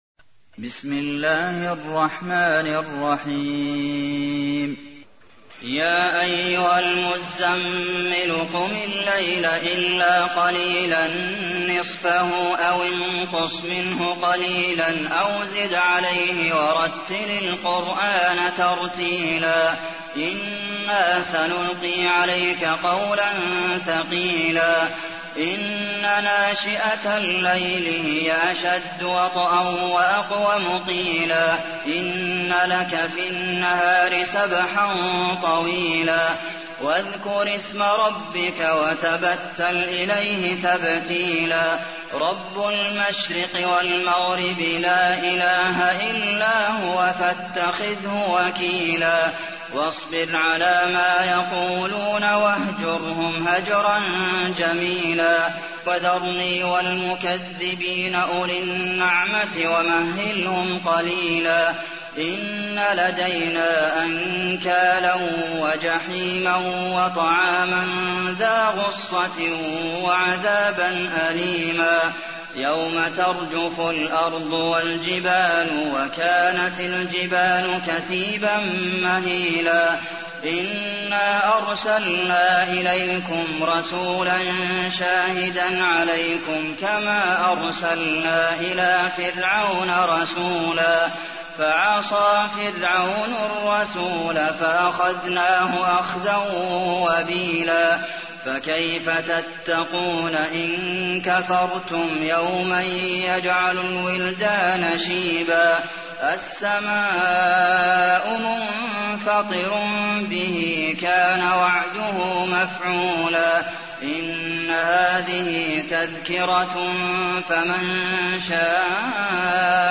تحميل : 73. سورة المزمل / القارئ عبد المحسن قاسم / القرآن الكريم / موقع يا حسين